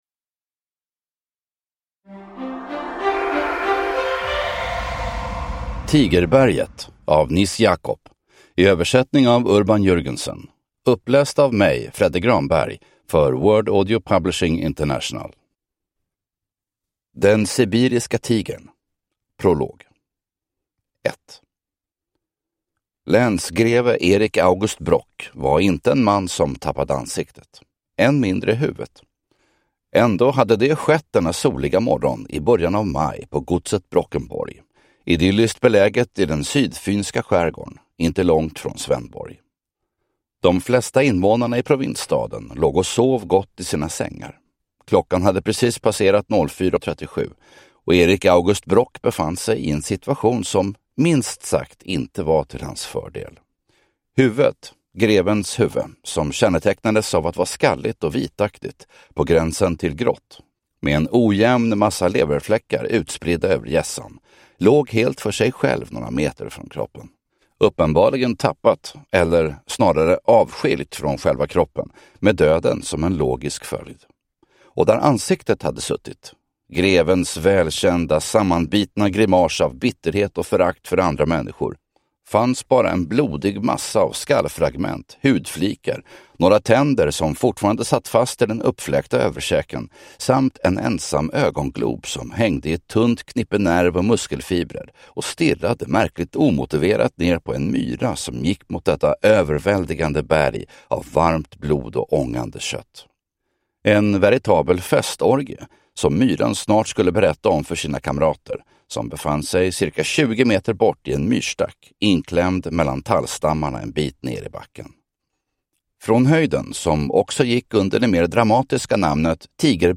Deckare & spänning
Ljudbok